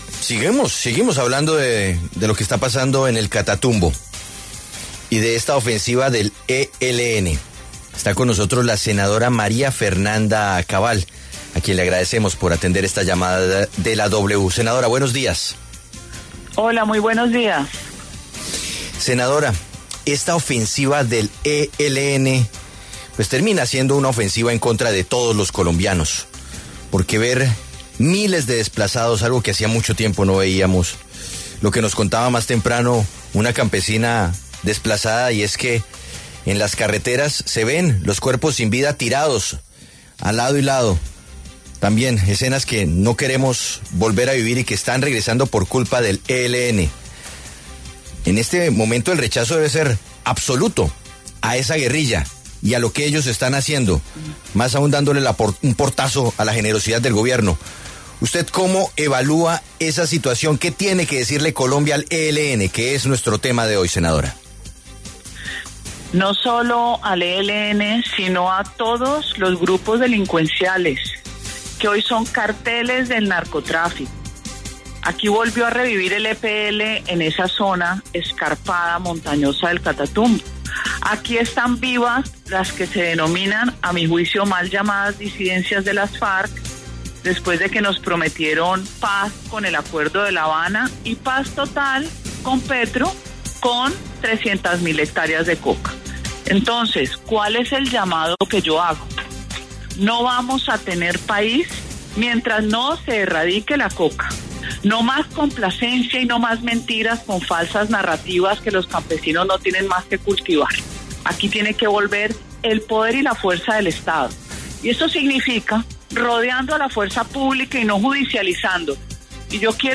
La senadora del Centro Democrático María Fernanda Cabal pasó por los micrófonos de La W, con Julio Sánchez Cristo, para hablar de la crisis de violencia en el Catatumbo, donde decenas de personas fallecieron y otras fueron desplazas.